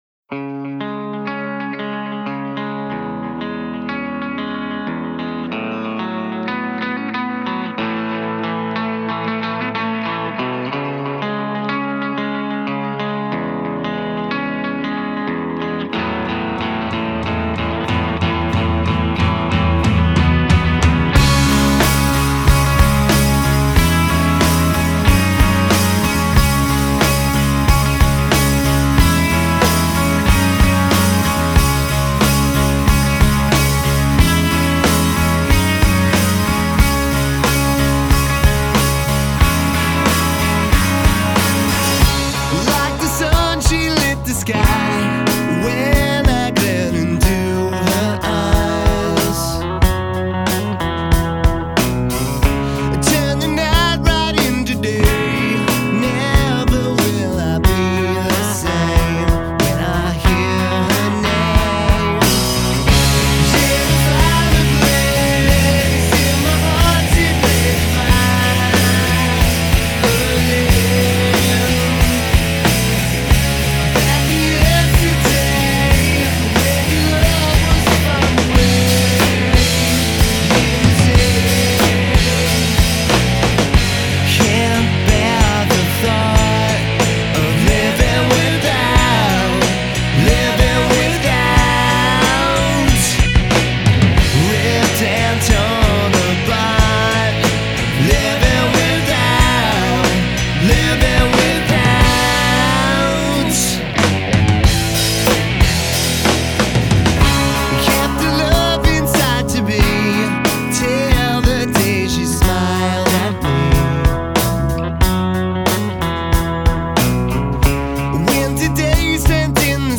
10 tracks of cubicle rock plus a special bonus track...